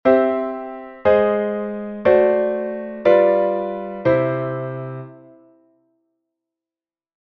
Eine Folge der Akkorde: C-Dur – As-Dur – D-Dur – G-Dur – C-Dur, die ein sehr wohl verständliches Sätzchen bildet, ist nach dieser Chiffrierung kaum zu verstehen; obgleich sie in keiner Weise eine Modulation nach einer anderen Tonart bedeutet, wäre man doch gezwungen, den As-Dur-Akkord im Sinn von F-Moll oder C-Moll und den D-Dur-Akkord im Sinn von G-Dur aufzufassen: